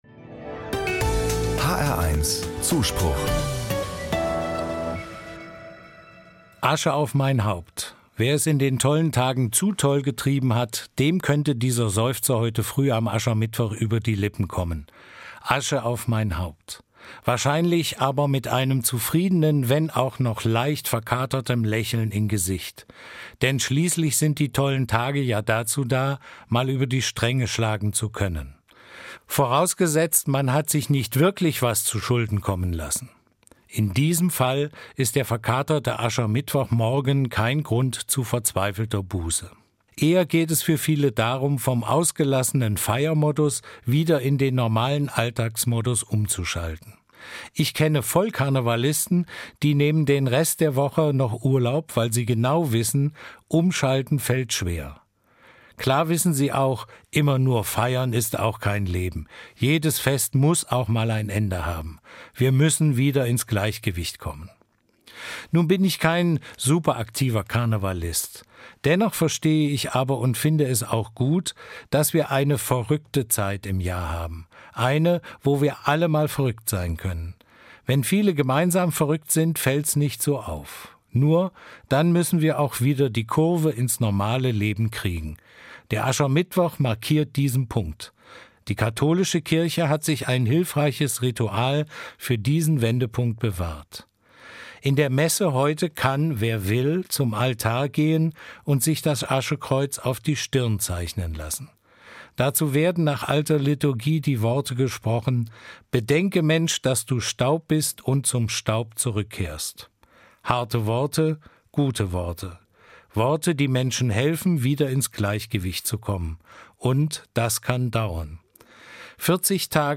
Evangelischer Pfarrer i. R., Kassel